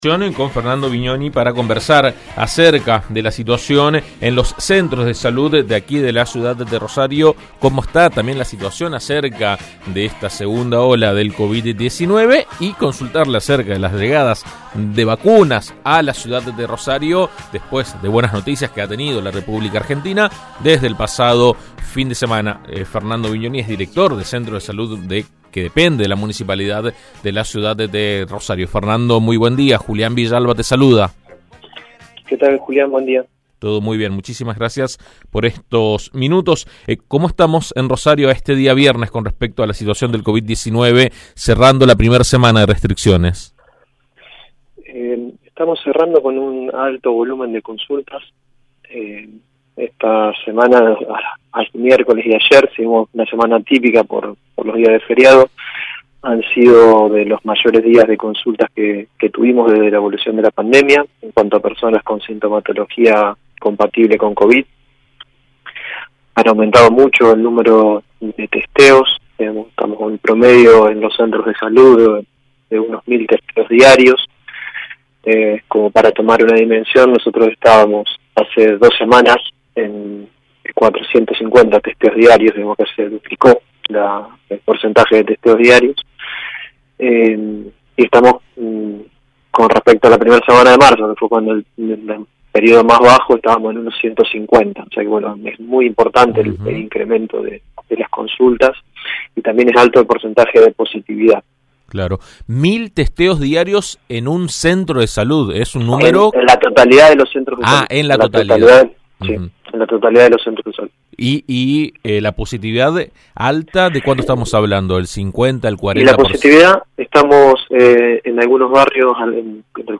La situación del sistema de salud de la ciudad sigue siendo crítica, con una altísima demanda y agotamiento de los trabajadores. AM 1330 habló al respecto con el Director de Centros de Salud municipal